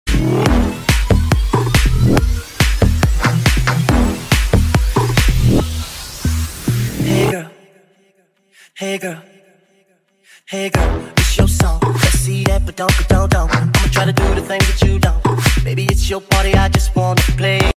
Bass đập quấn quá nhờ sound effects free download